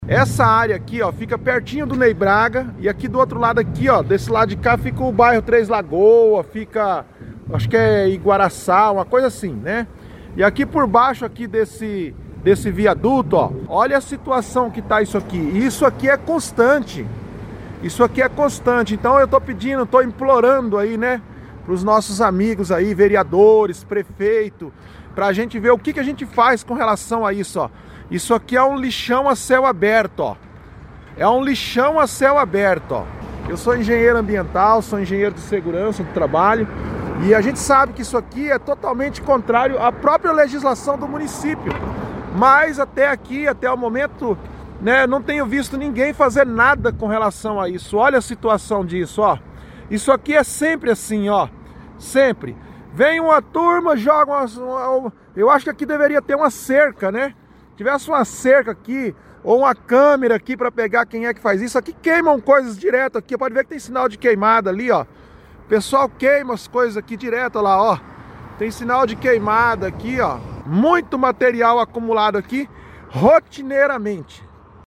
Ouça o desafabo do ouvinte, em que ele sugere também a colocação de câmeras no local: